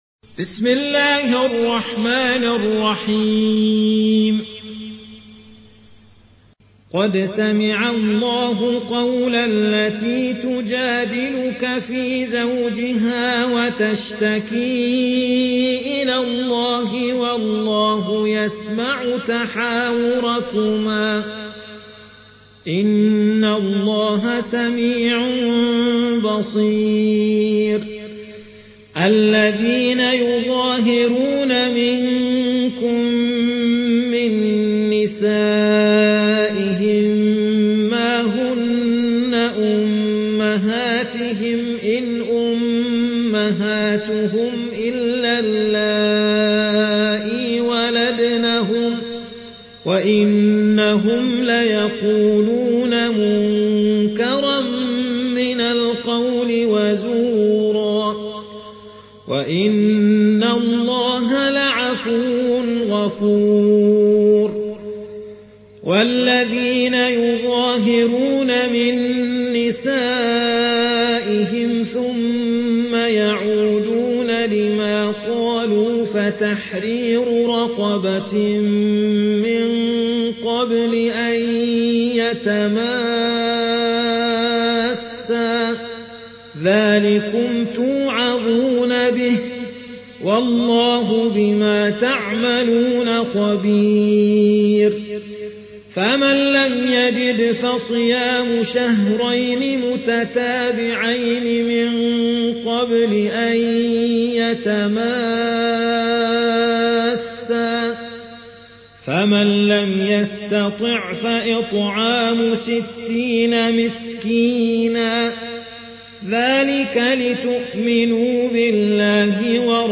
58. سورة المجادلة / القارئ